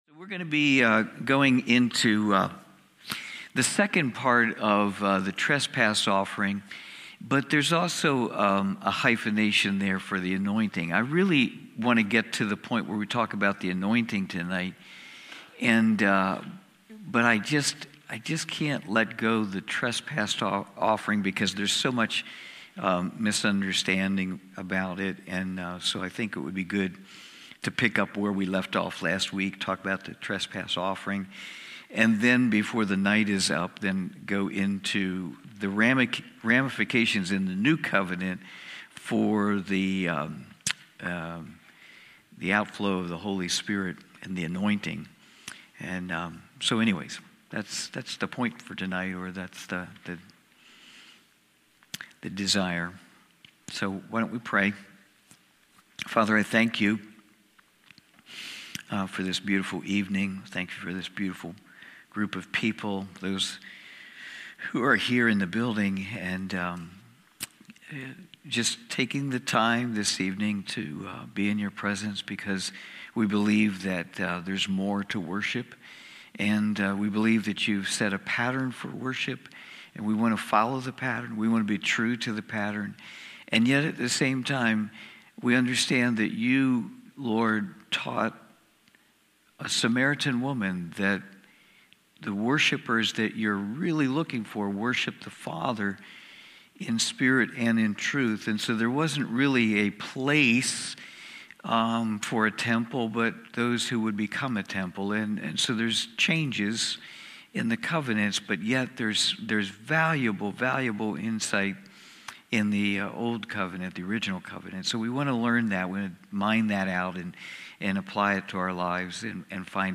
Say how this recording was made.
Cornerstone Fellowship Wednesday evening Bible study.